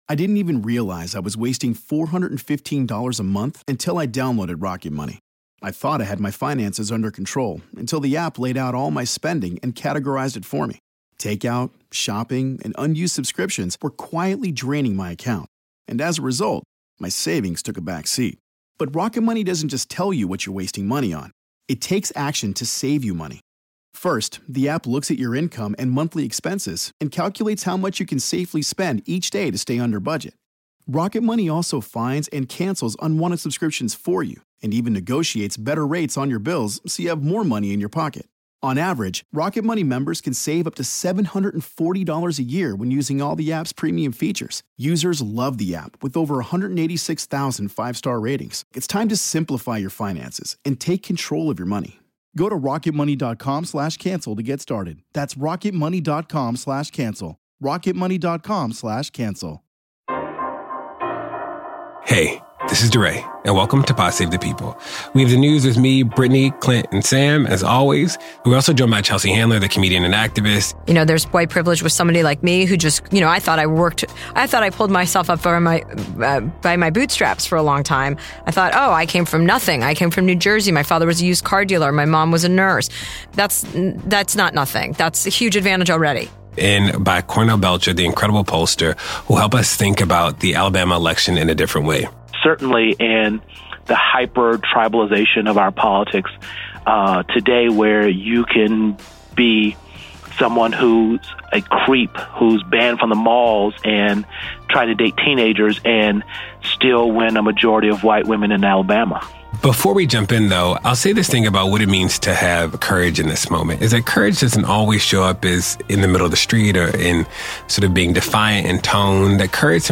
DeRay gets together with Chelsea Handler to talk about the world, allyship, and being both funny and angry (swearing abounds). Political pollster Cornell Belcher joins DeRay to discuss the synergies between Obama’s win and Doug Jones’.